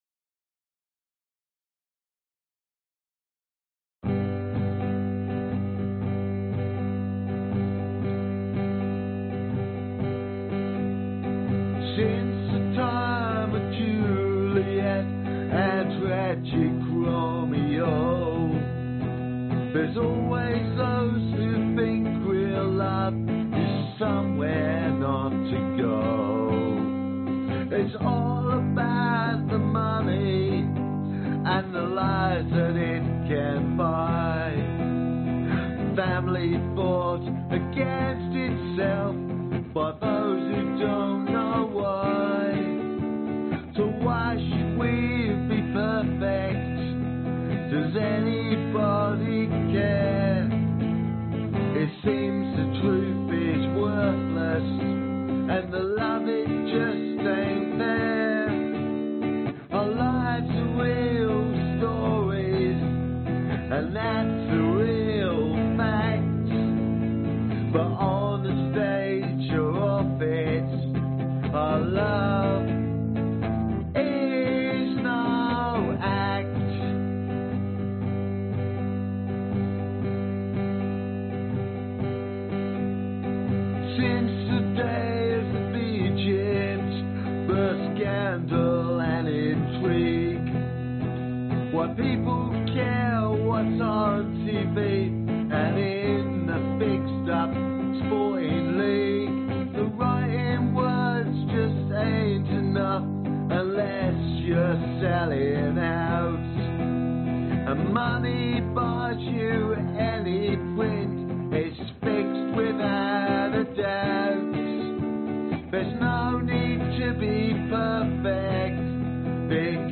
Tag: 贝司 单簧管 女声 吉他 男声 钢琴